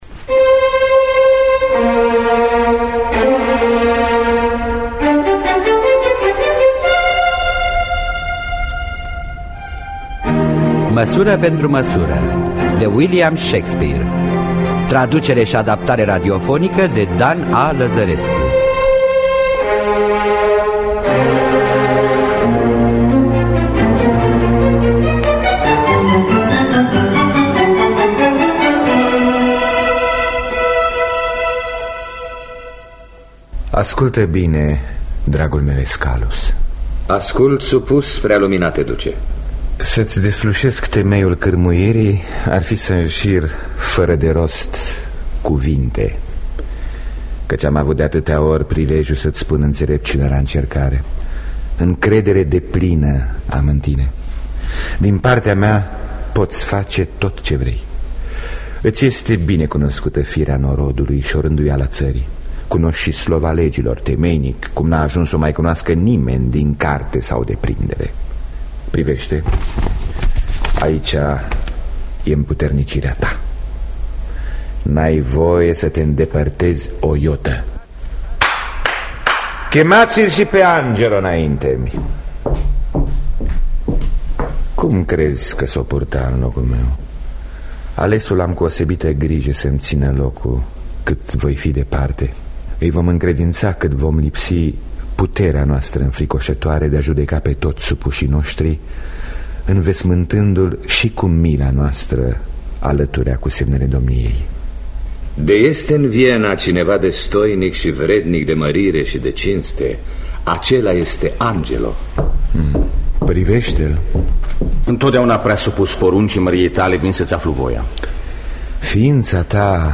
Măsură pentru măsură de William Shakespeare – Teatru Radiofonic Online